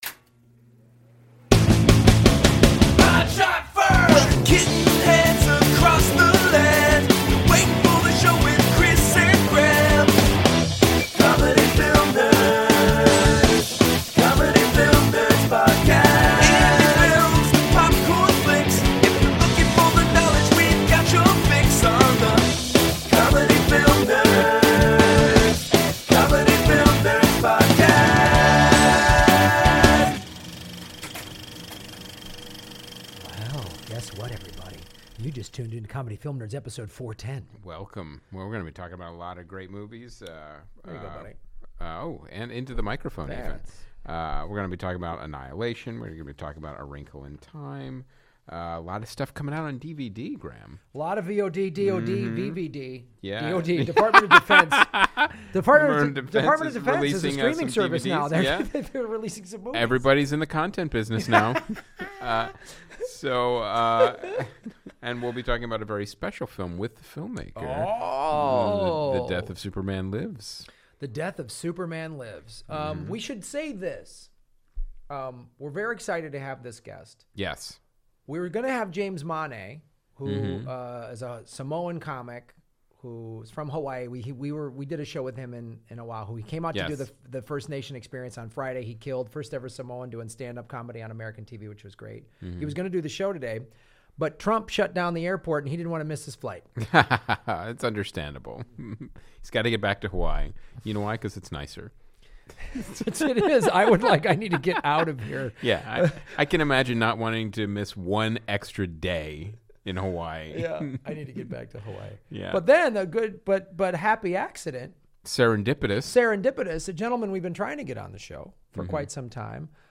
into the ATC Studio.